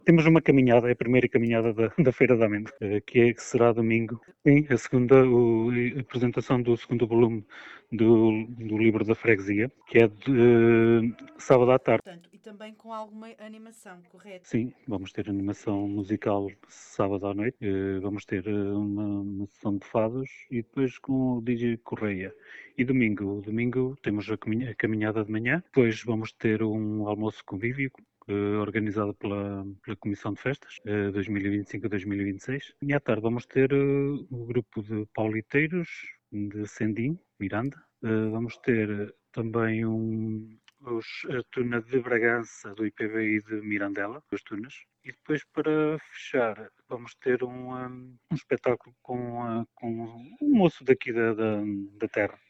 Esta edição a novidade da programação é uma caminhada que vai contra com cerca de 30 participantes, como adiantou, Inácio Oliveira, presidente da junta de freguesia de Amendoeira: